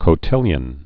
(kō-tĭlyən, kə-)